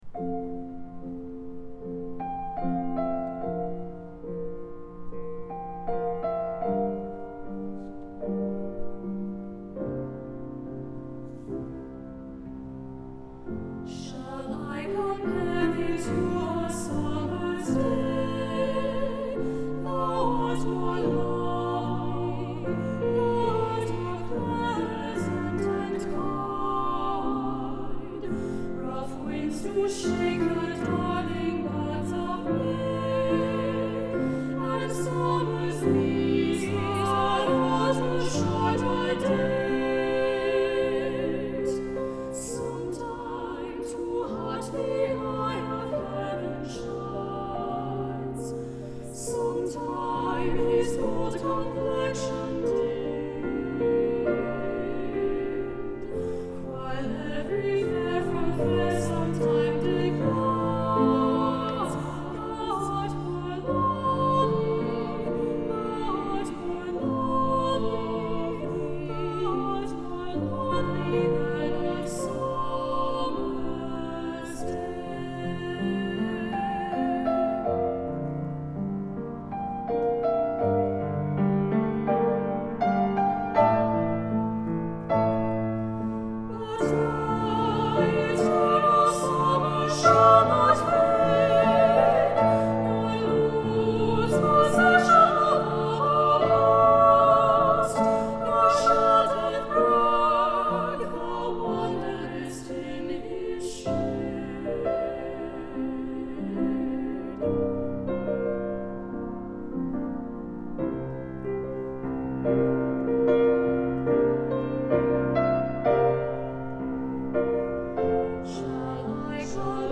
Voicing: SSA
Instrumentation: Piano